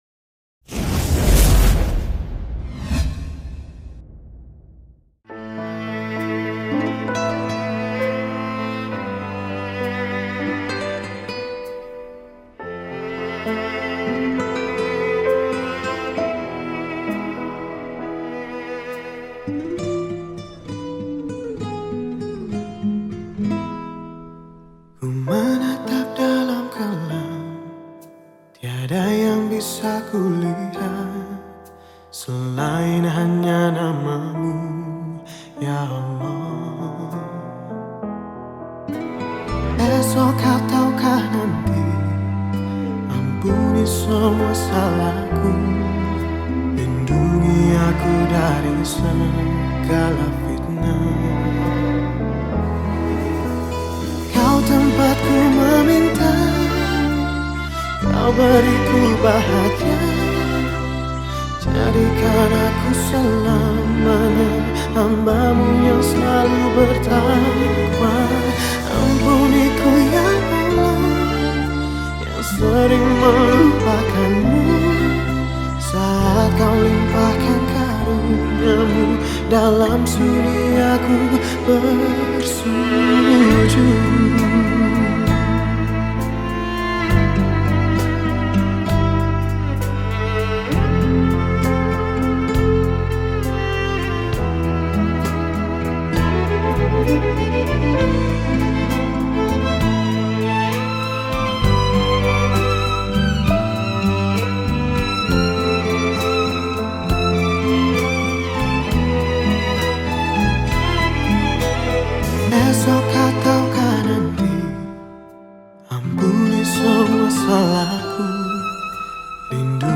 Indonesian Songs , Nasyid Songs